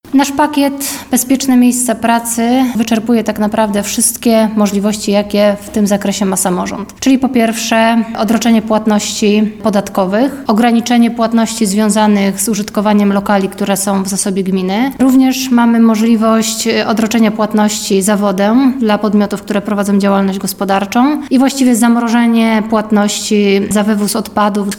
To pomoc miasta dla lokalnych właścicieli firm, którzy ponieśli straty w wyniku epidemii – mówi wiceprezydent Radomia Katarzyna Kalinowska.